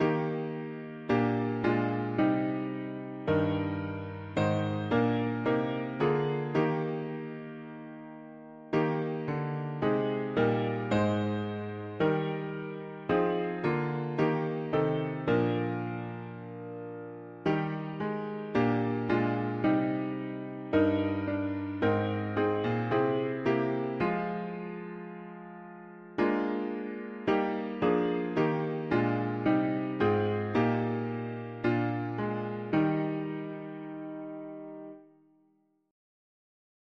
When other helpers fail, and comforts flee, Help of the helpless, o… english theist 4part evening death chords
Key: E-flat major